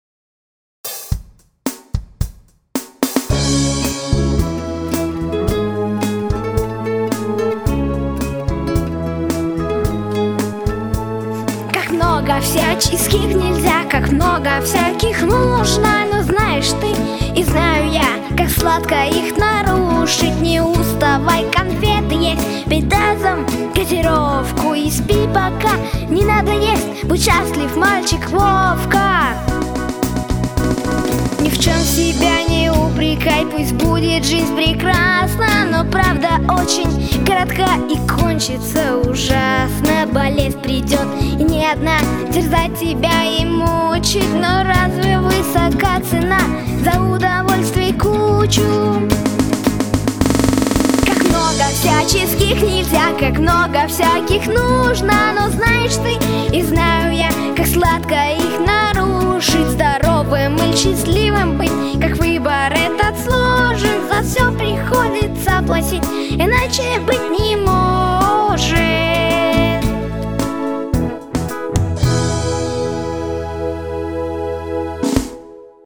III Театральный Фестиваль начальной школы